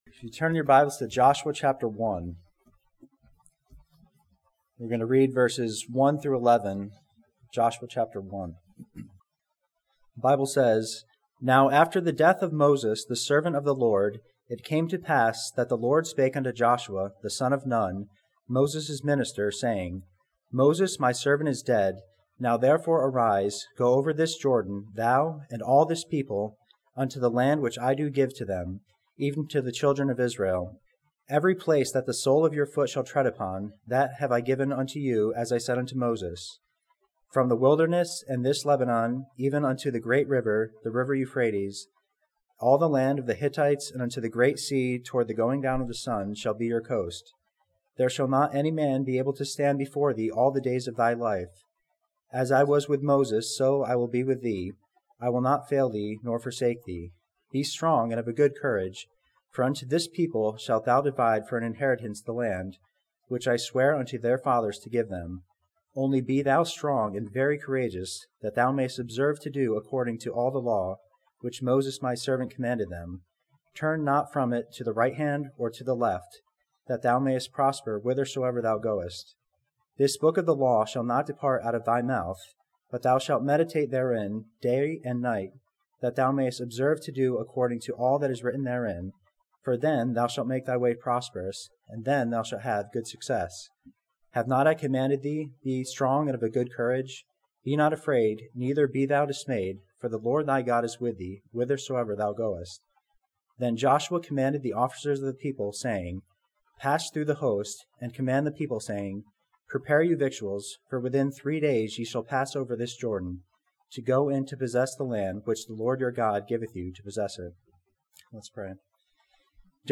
This sermon from Joshua chapter one challenges believers to have a fearless forward following faith in God.